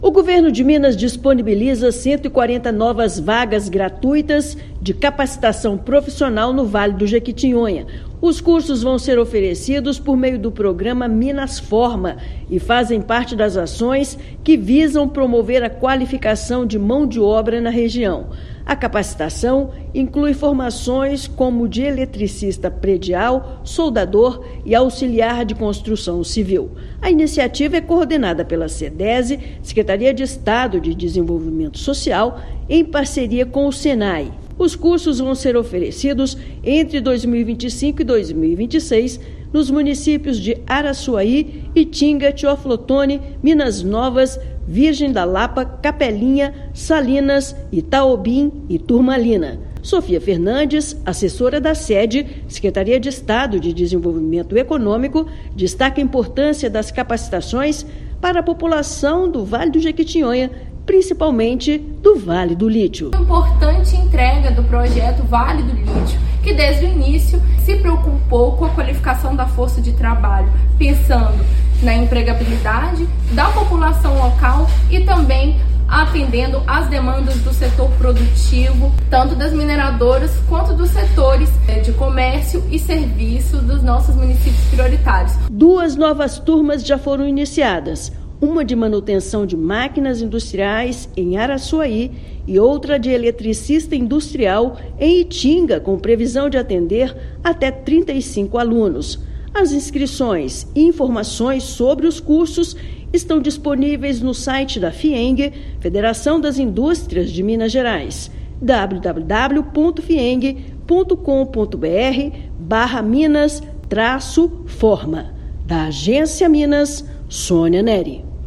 [RÁDIO] Governo de Minas abre 140 vagas de formação profissional no Vale do Jequitinhonha
Programa Minas Forma, coordenado pela Sedese-MG, garante formação técnica para moradores de região beneficiada pelo projeto Vale do Lítio. Ouça matéria de rádio.